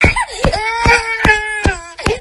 Play Screaming Baby Short - SoundBoardGuy
Play, download and share Screaming Baby Short original sound button!!!!
screaming-baby-short.mp3